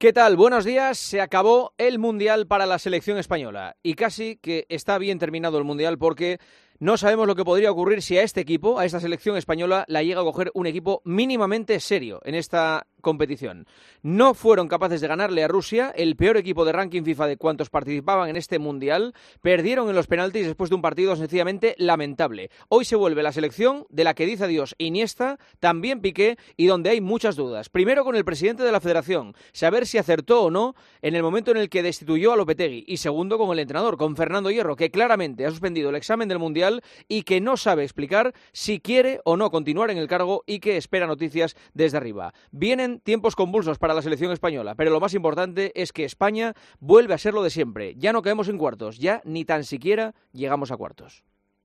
El director de 'El Partidazo' de COPE analiza la eliminación de España en el Mundial de Rusia